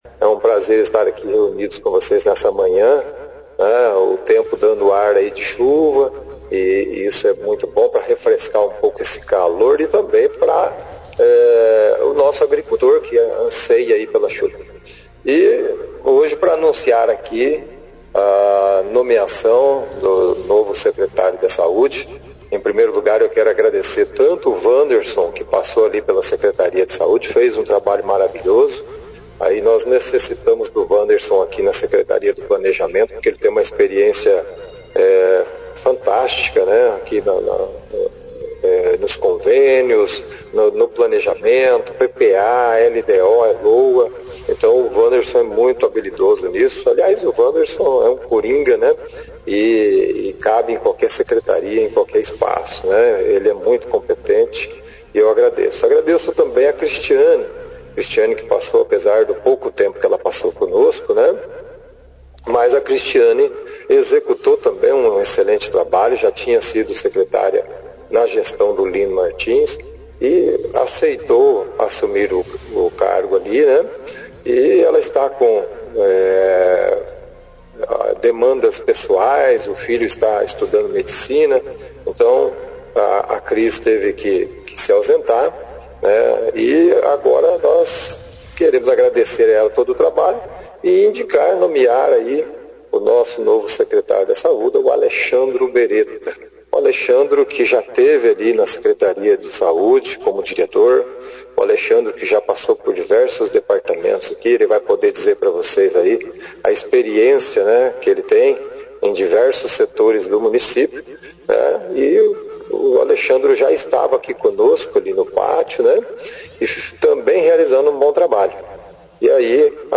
Na manhã desta quarta-feira, 4 de outubro, o prefeito Jaelson Matta convocou a imprensa local para anunciar o novo secretário de saúde, Alexandro Beretta, que assumirá o cargo em substituição a Cristiane Caçador de Araújo. Cristiane alegou problemas particulares e solicitou sua exoneração do cargo. A apresentação foi acompanhada pela nossa equipe de reportagem do jornal Operação Cidade.